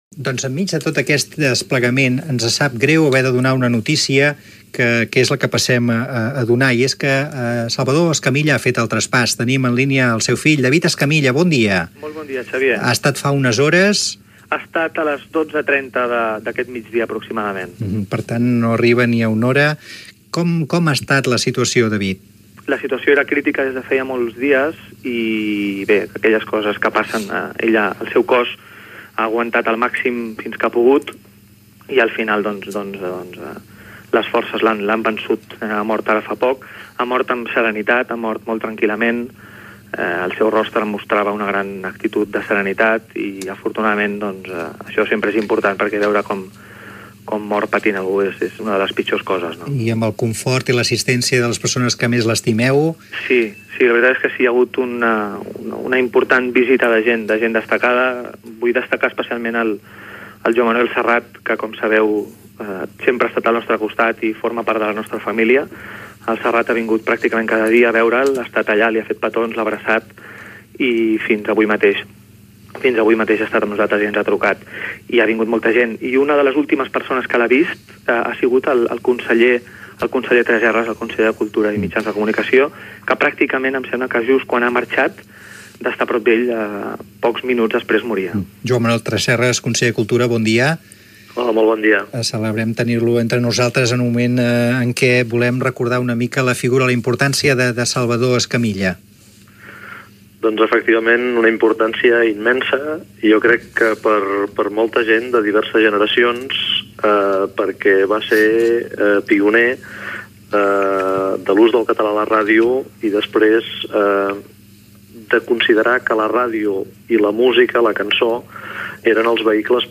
Intervenció del conseller de Cultura Joan Manuel Tresserras
Entreteniment